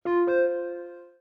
Samsung Galaxy Bildirim Sesleri - Dijital Eşik